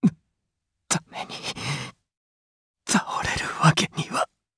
Siegfried-Vox_Dead_jp.wav